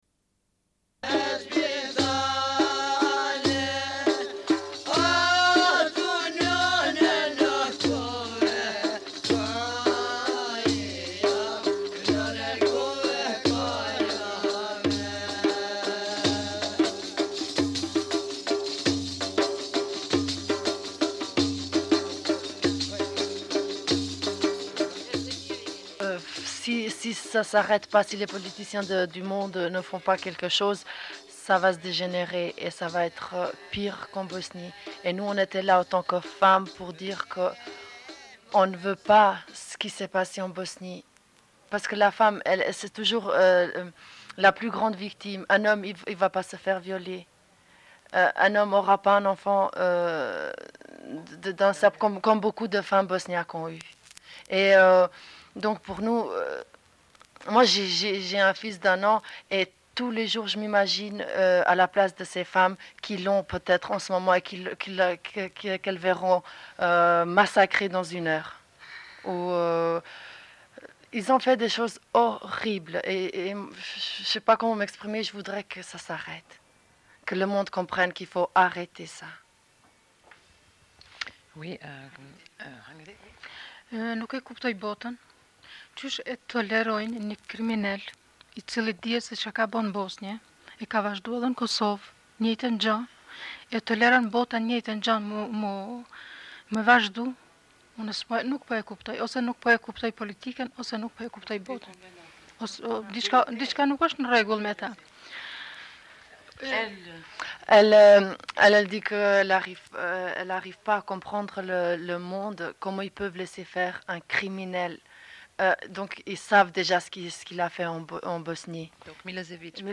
Suite de l'émission : rencontre avec cinq femmes kosovares, diffusion d'entretiens.